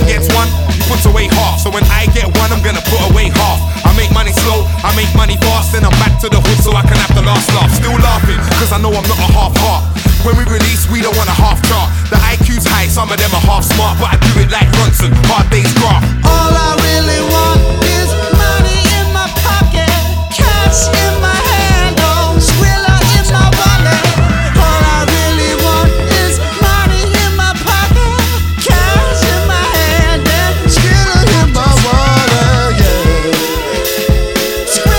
Hip-Hop Rap Dance Garage Rap House
Жанр: Хип-Хоп / Рэп / Танцевальные / Хаус